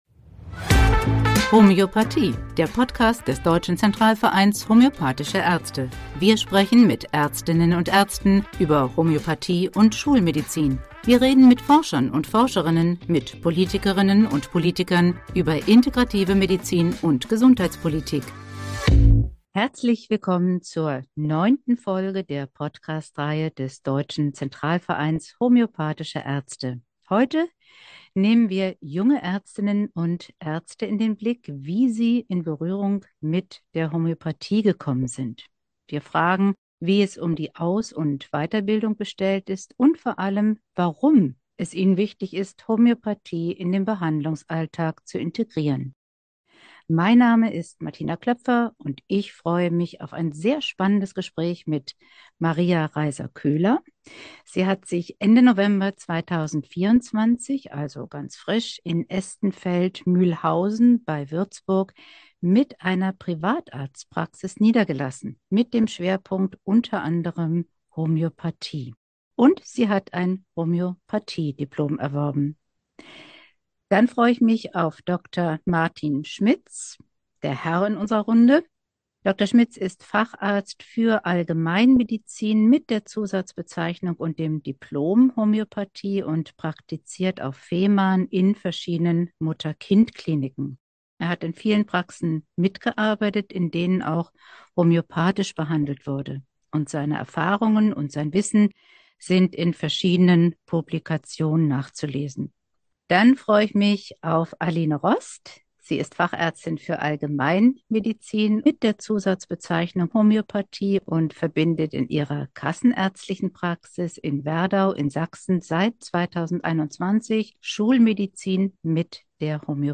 In der 9. Folge des DZVhÄ-Podcast erzählen jüngere Ärztinnen und Ärzte, warum sie in ihren Praxen auf Homöopathie setzen, erklären die Möglichkeiten der Weiterbildung Homöopathie und gehen der Frage nach, ob es bestimmte Fachgebiete gibt, in denen Homöopa...